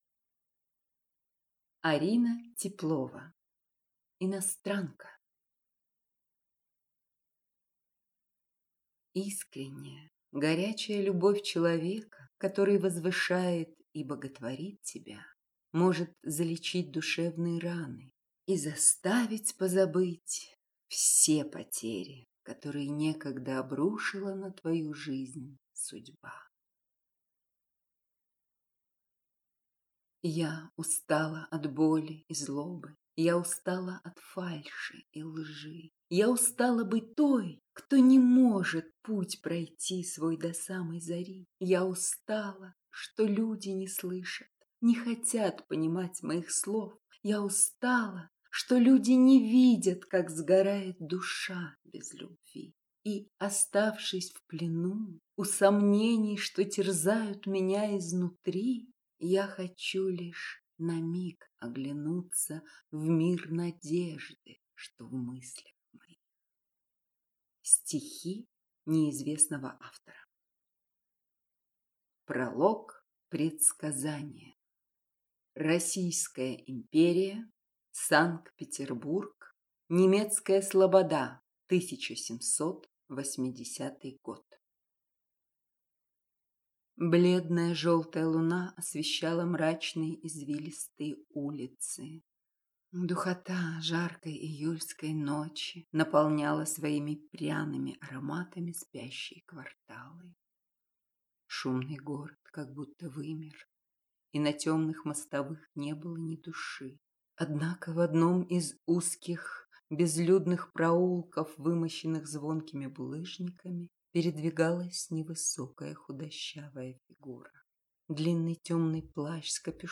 Аудиокнига Иностранка | Библиотека аудиокниг